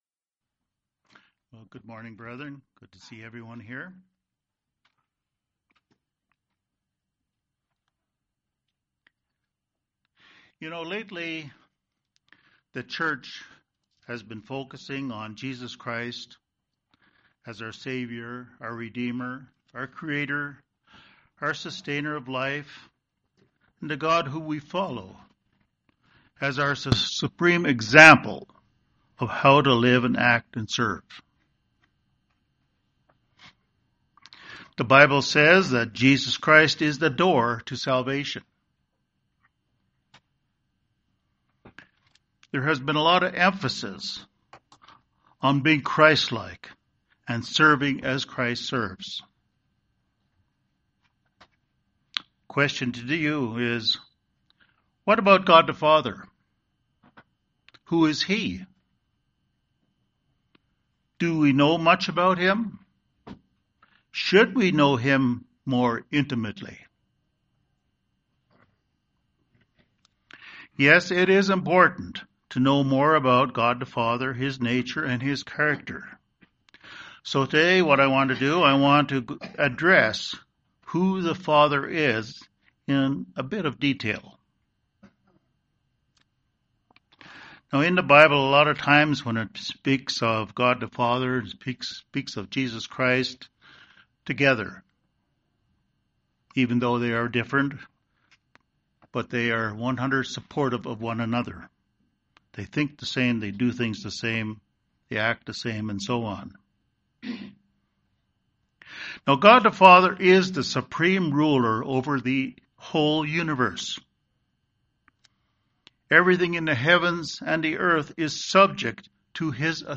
This sermon addresses in some detail who God the Father is. We know God the Father is supreme ruler over the entire universe, everything in the heavens and the earth is subject to Him.